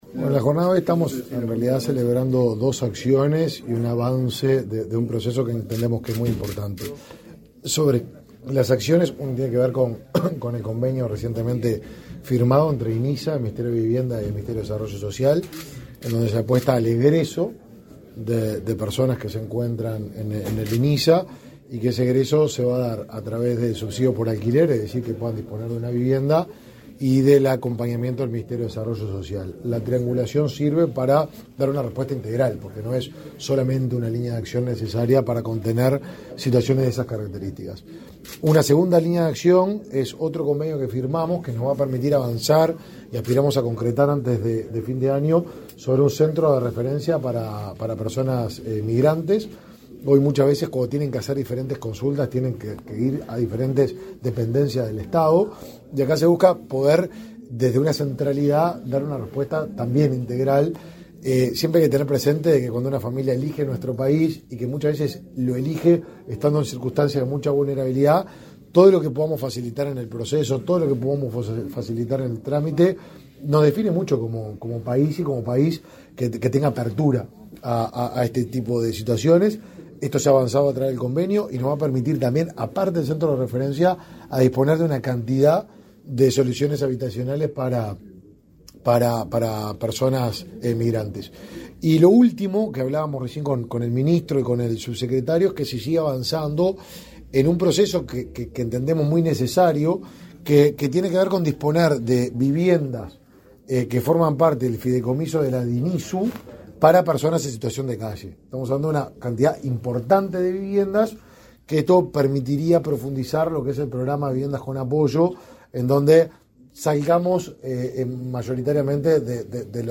Declaraciones a la prensa del ministro del Mides, Martín Lema